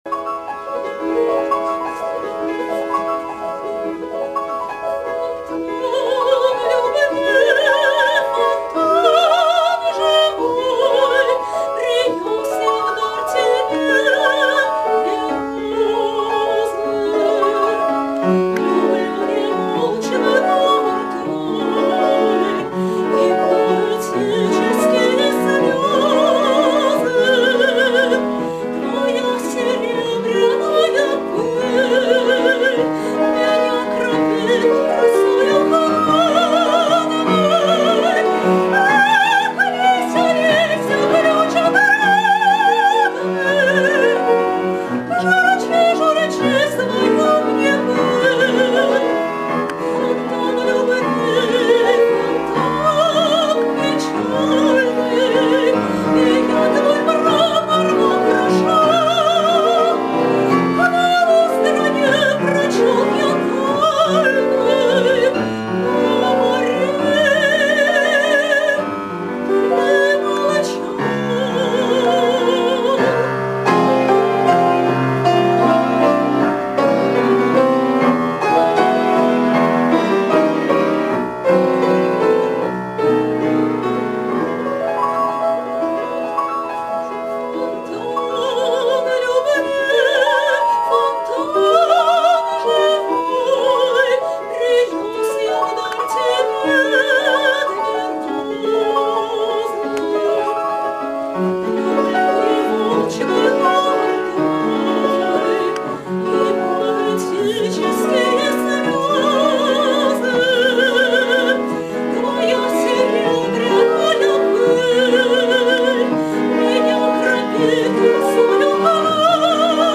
драматическое сопрано